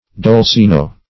Dolcino synonyms, pronunciation, spelling and more from Free Dictionary.
Search Result for " dolcino" : The Collaborative International Dictionary of English v.0.48: Dolcino \Dol*ci"no\, or Dulcino \Dul*ci"no\, n. [Cf. It. dolcigno sweetish.]